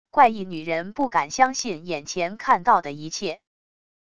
怪异女人不敢相信眼前看到的一切wav音频